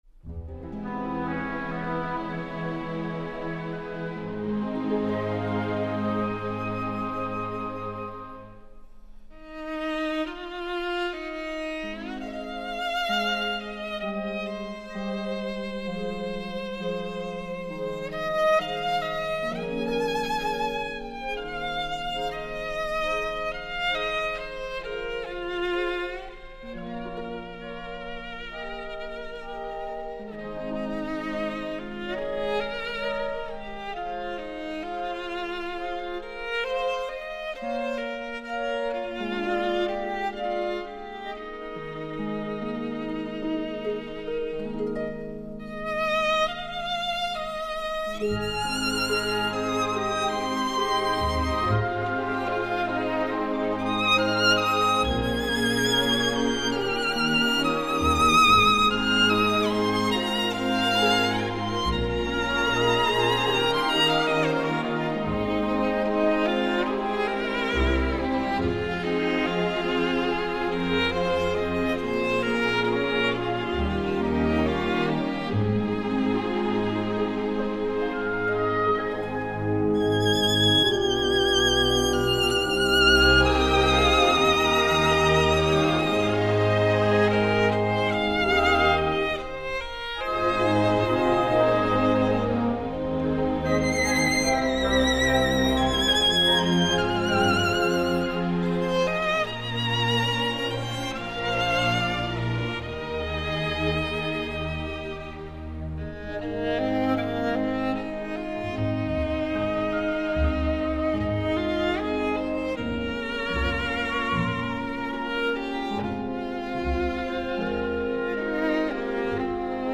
[2005-8-6]另一种痛——忘不了，忘不了。。。（小提琴演奏曲）